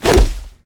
grenade throw.ogg